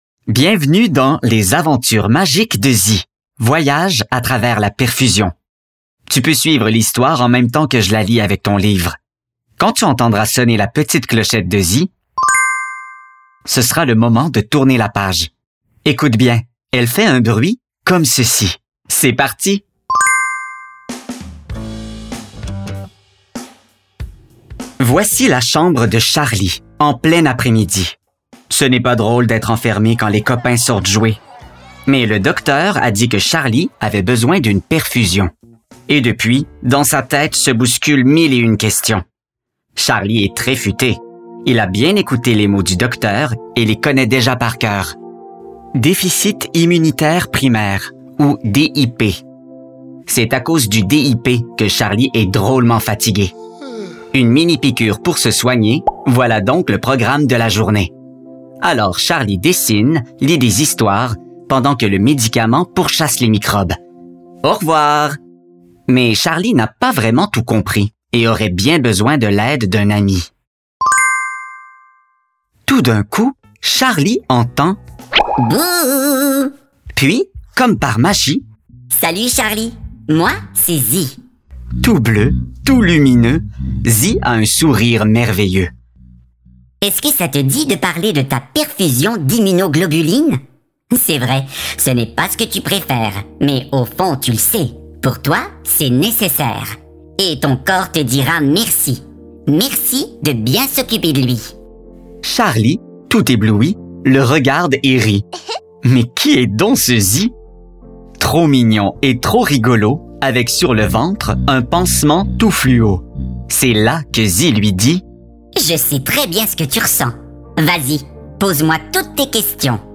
LE LIVREAUDIO
Hizentra-Audiobook-FR.mp3